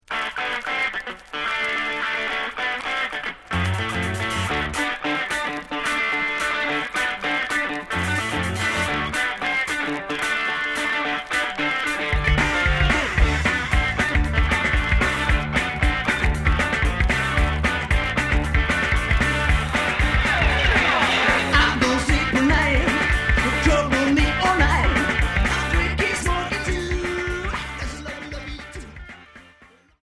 Rock Unique 45t retour à l'accueil